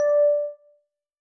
bell